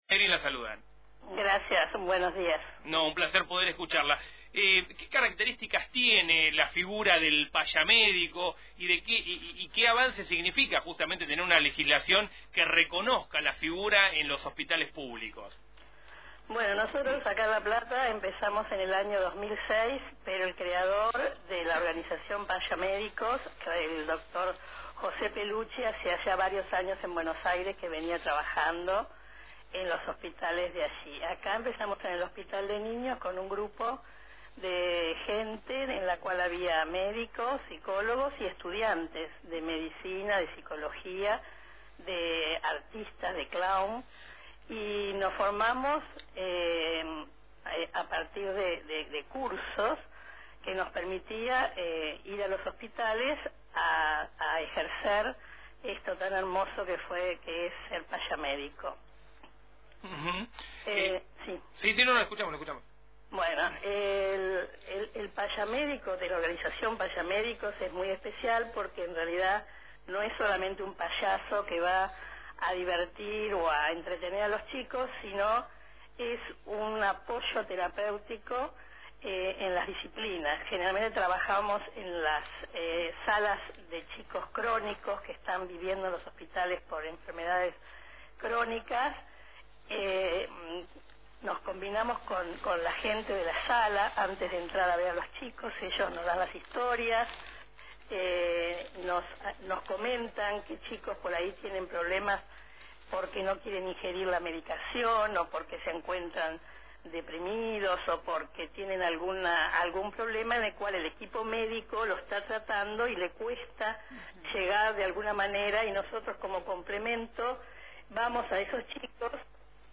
En diálogo con Radio Provincia